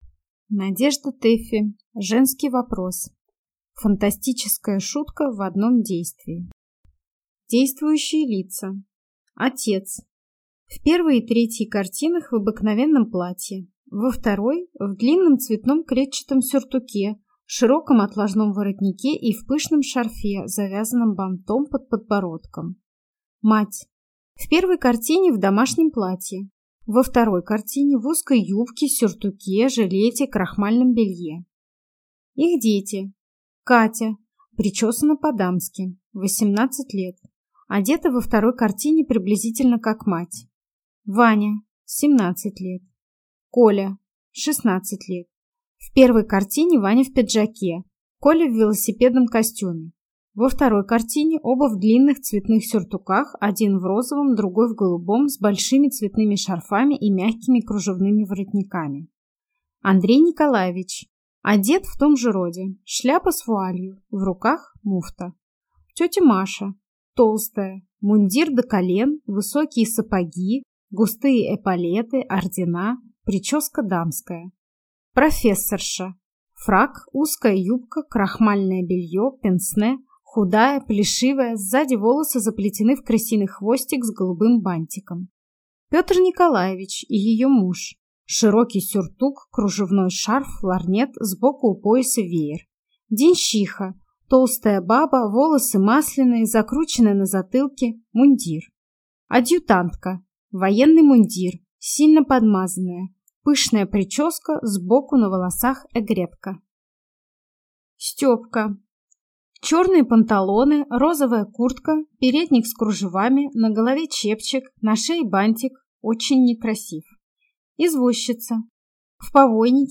Аудиокнига Женский вопрос | Библиотека аудиокниг